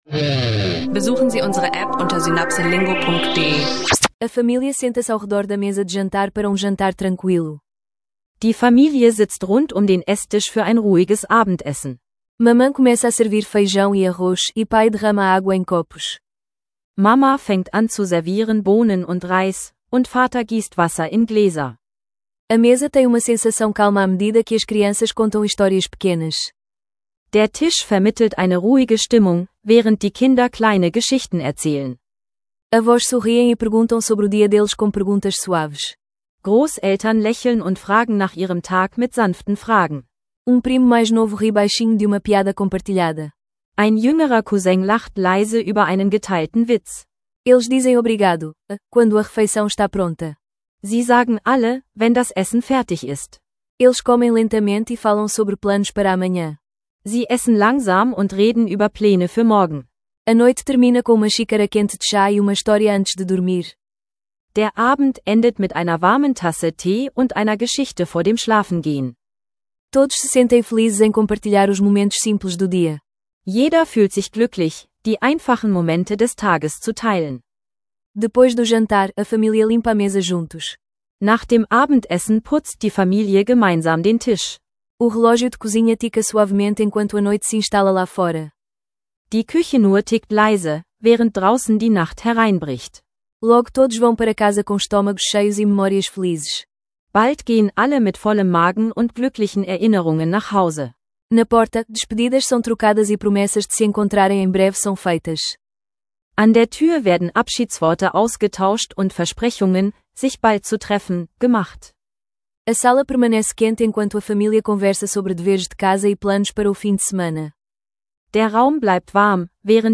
Lerne Portugiesisch im Alltag: Familienessen, Social Media und Unternehmensdrama – praxisnahe Dialoge, Vokabeln und Redewendungen.